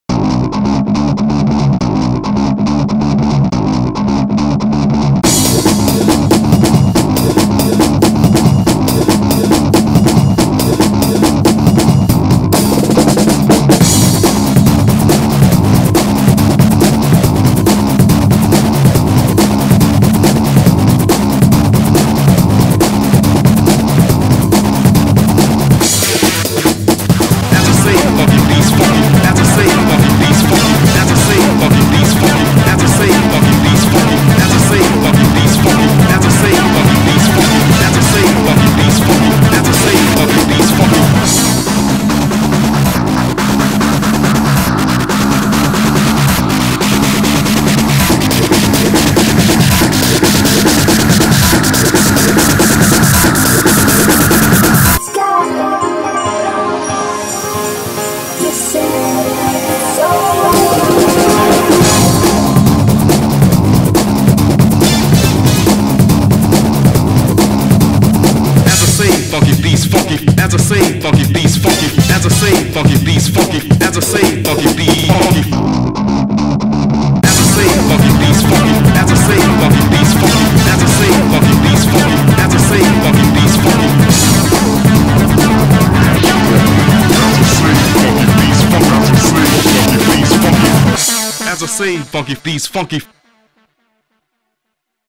BPM140
Audio QualityPerfect (High Quality)
As I say, funky beats funky!